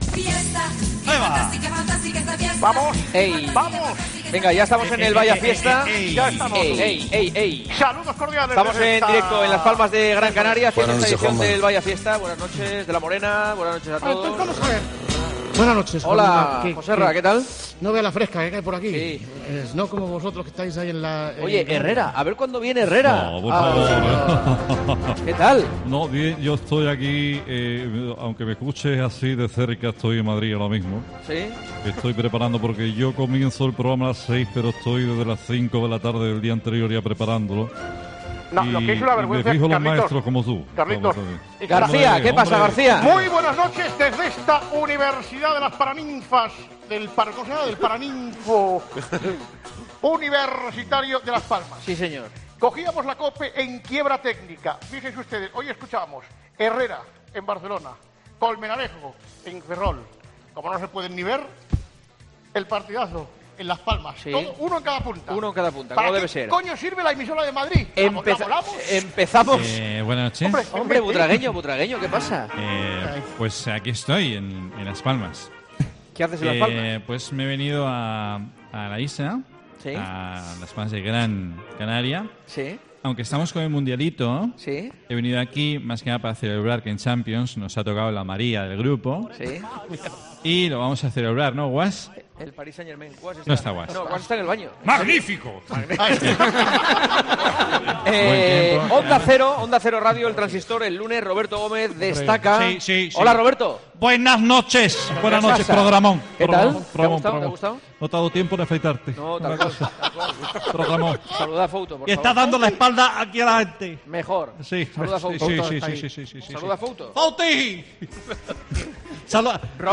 AUDIO: El Grupo Risa, en El Partidazo de COPE desde Las Palmas. Con un especial de gazapos relacionados con la UD Las Palmas.